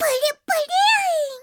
File:Jigglypuff voice sample JP.oga
Jigglypuff_voice_sample_JP.oga.mp3